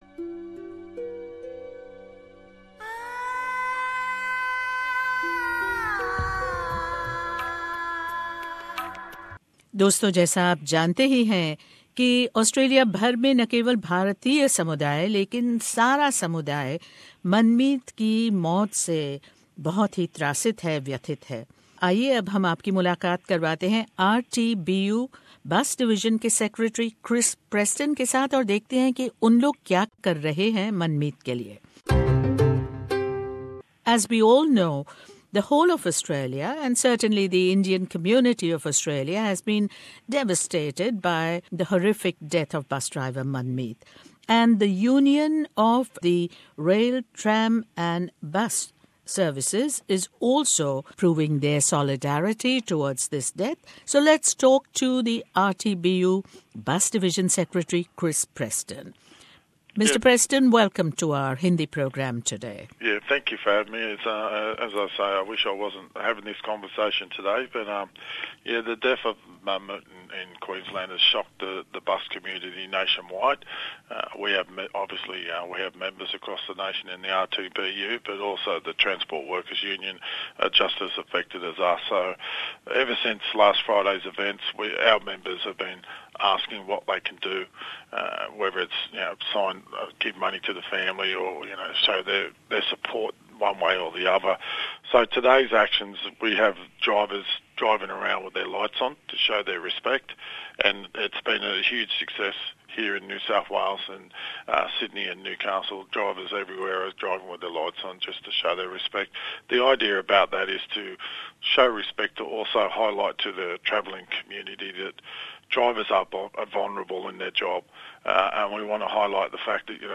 एक्सक्लूसिव भेंटवार्ता